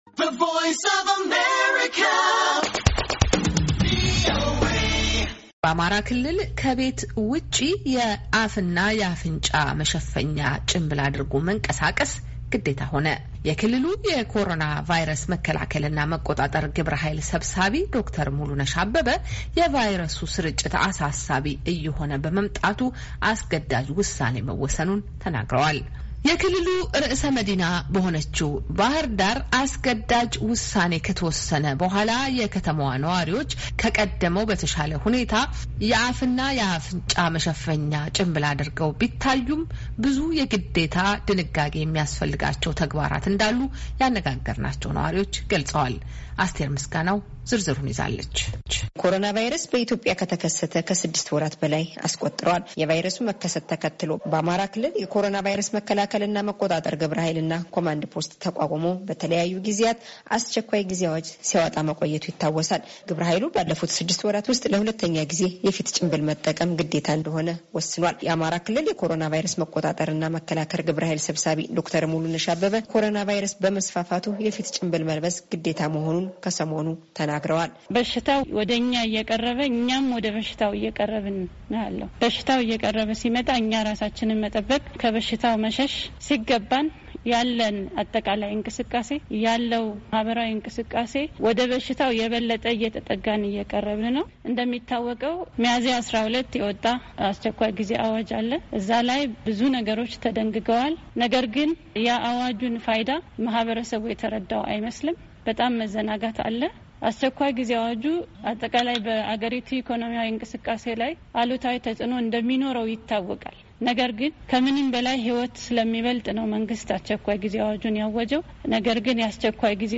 የክልሉ ርዕሰ መዲና በሆነችው ባህር ዳር ግን አስገዳጅ ውሣኔከተወሰነ በዋላ የከተማዋ ነዋሪዎች ከበፊቱ የተሻለ ተጠቃሚ ቢታይም ብዙ የግዴታ ድንጋጌ የሚያስፈልጋቸው ተግባራት እንዳሉ ያነጋገርናቸው ነዋሪዎች ገልፀዋል።